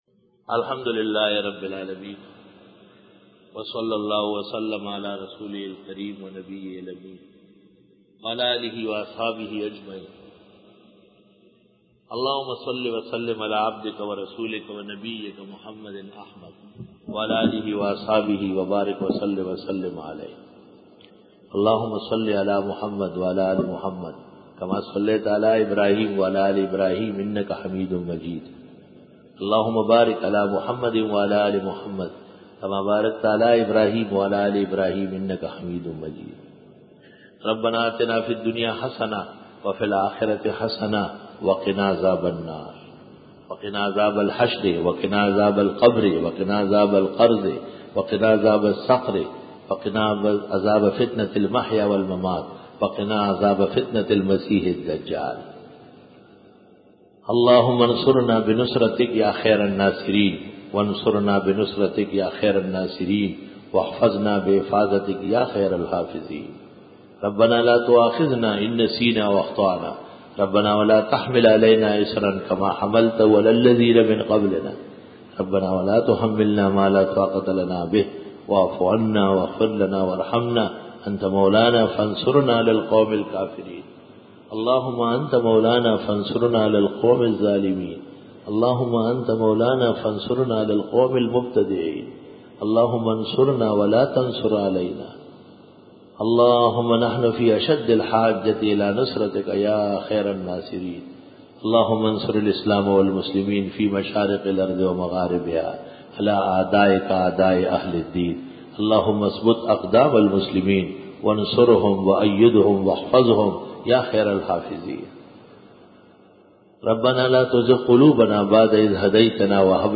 دُعاء 2005 Bayan